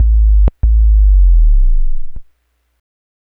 bass03.wav